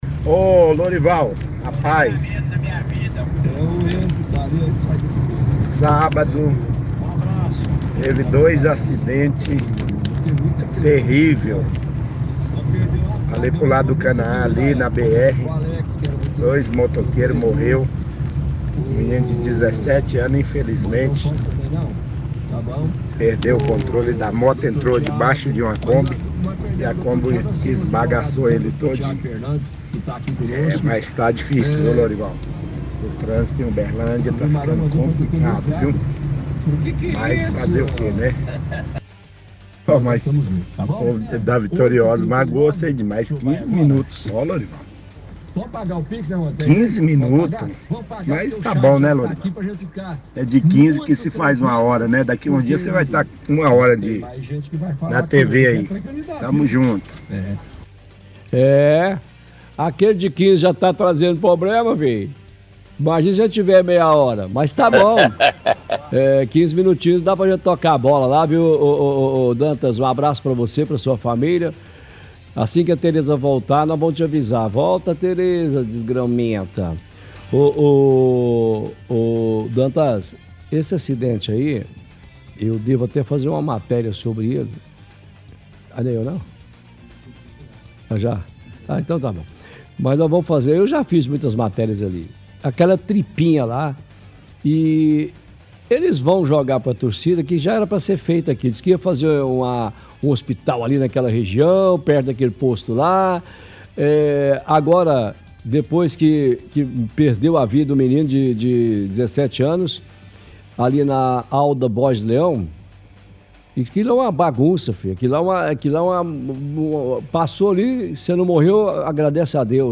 – Ouvinte relata que aconteceram acidentes no Canaã.